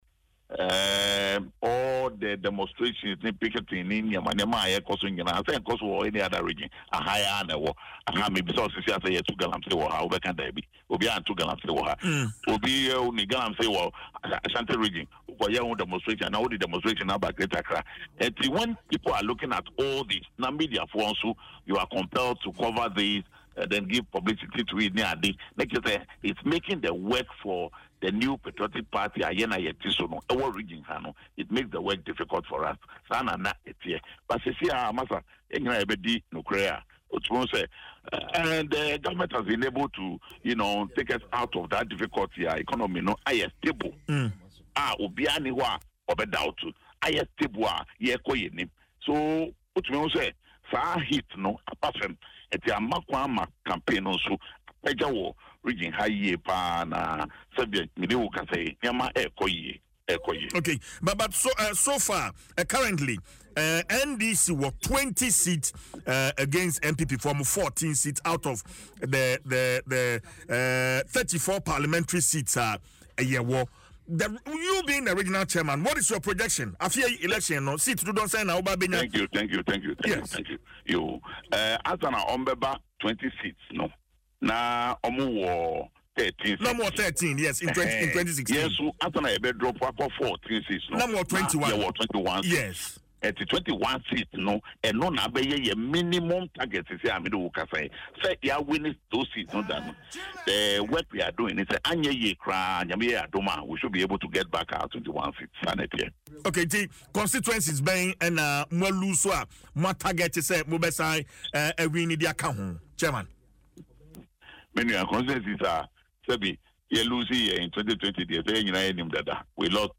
In an interview on Adom FM’s Dwaso Nsem